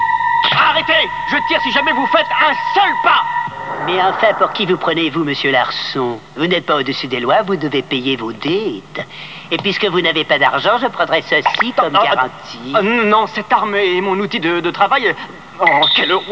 Larson doit payer ses dettes ! Réplique tirée de l'Anime City Hunter .